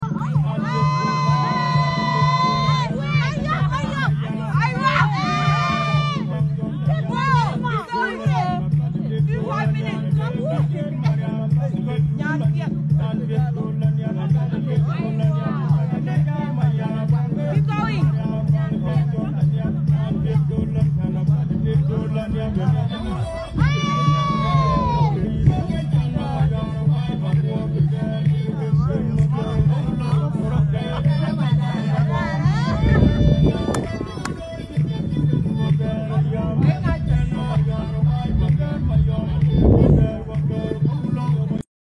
Dinka traditional dance
Dinka traditional dance from South Sudan. The Dinka, also referred to as Jieng, hold the distinction of being the largest ethnic tribe in South Sudan.